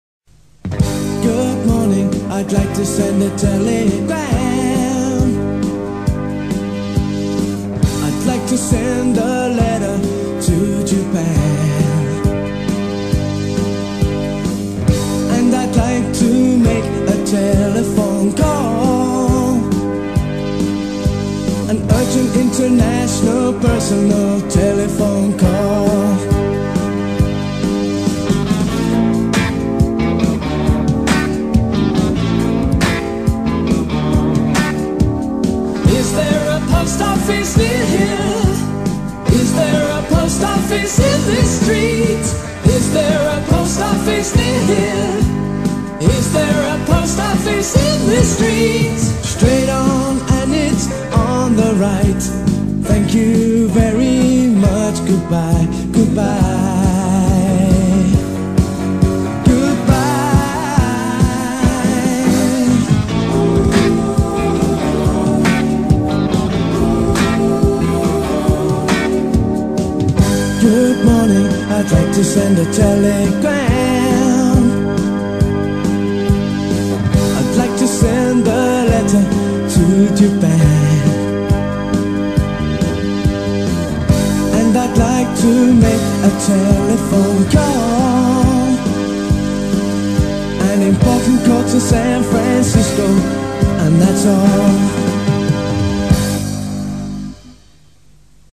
con esta canción no comercial